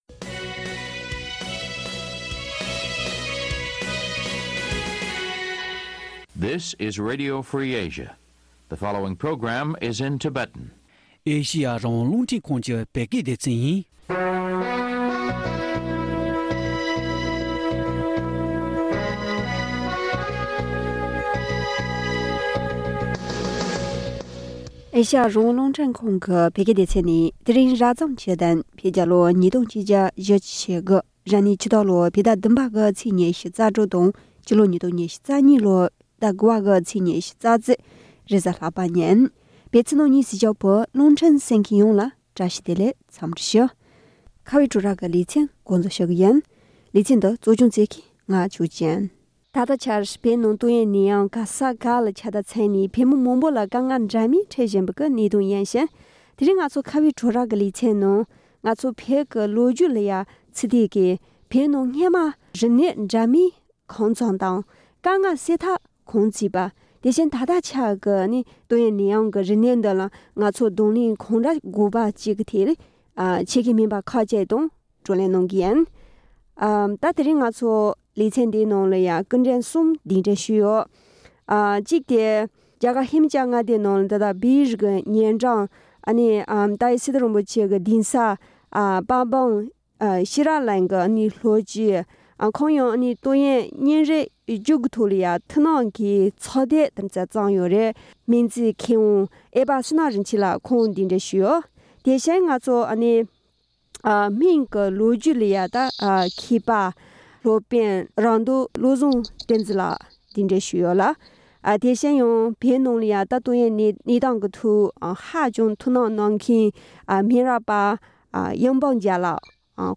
བོད་ནང་སྔོན་མར་རིམས་ནད་འདྲ་མིན་གང་བྱུང་དང་དཀའ་ངལ་སེལ་ཐབས་དེ་བཞིན་ད་ལྟའི་ཏོིག་དབྱིབས་ནད་ཡམས་ཀྱི་རིམས་ནད་འདིར་གདོང་ལེན་གང་འདྲ་དགོས་པ་བཅས་ཀྱི་ཐད་གླེང་མོལ་ཞུས་པ།